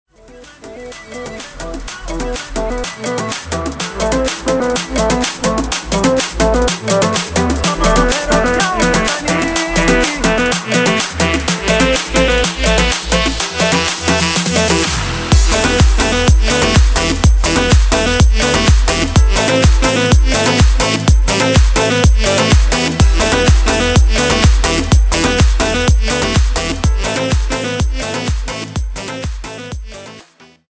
زنگ موبایل شاد